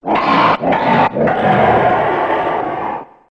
One of Bowser's voice clips in Mario Kart 64